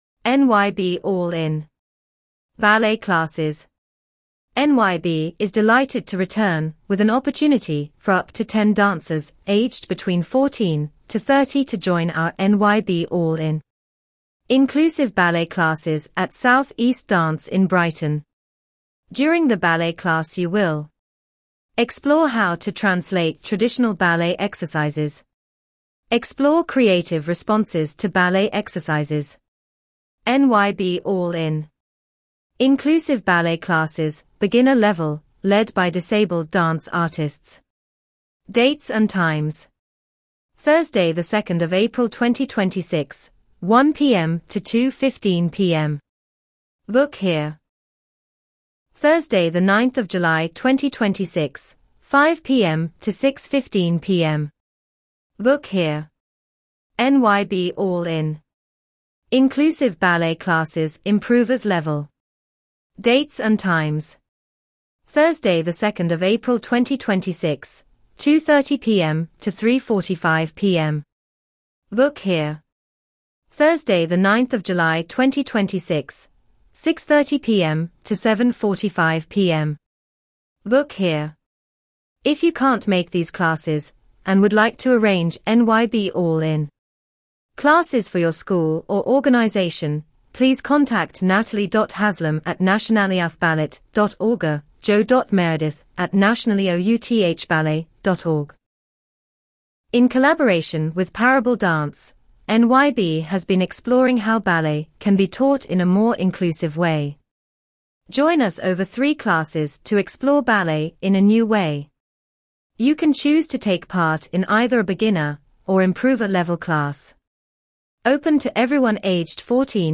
NYB-All-In-Class-Audio-Description.mp3